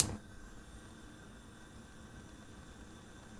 Gas Hob 03.wav